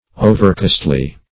Overcostly \O"ver*cost"ly\, a.